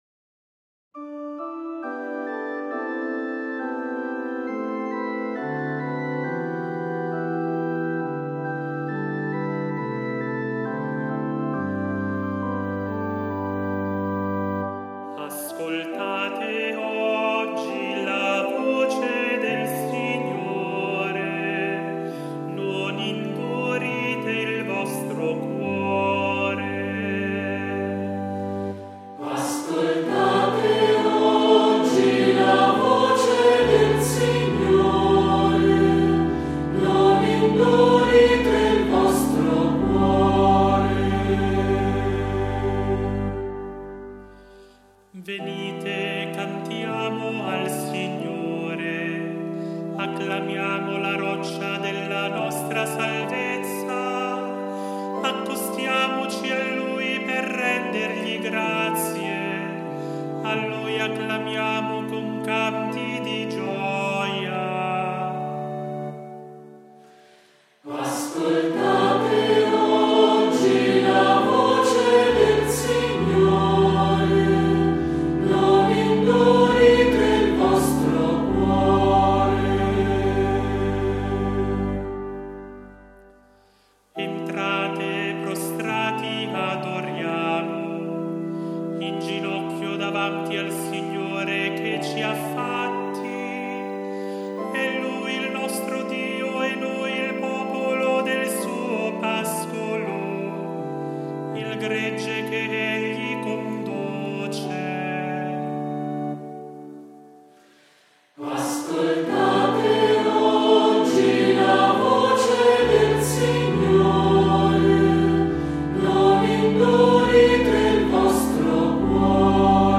IL SALMO RESPONSORIALE